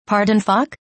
\ ˈpär-dᵊn-fək \
Download Pronunciation (MP3)